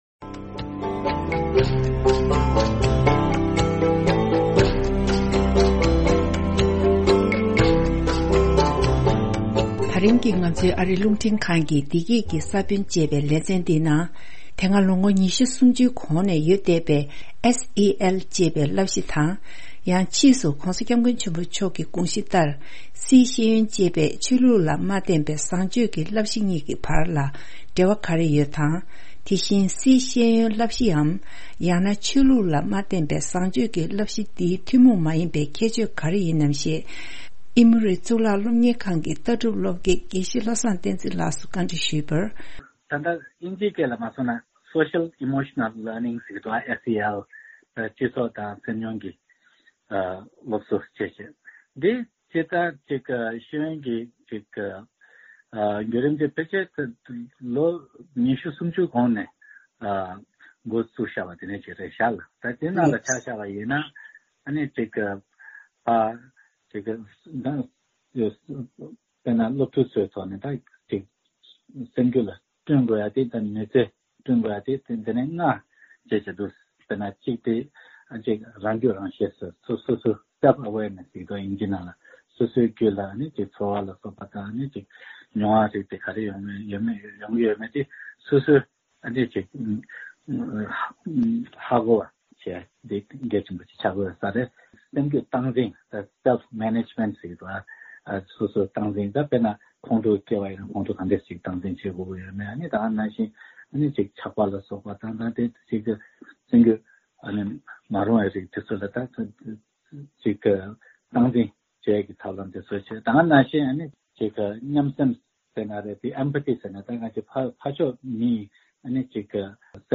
བཅར་འདྲི་ཞུས་པའི་དུམ་བུ་ཞིག་གསན་རོགས་གནང་།།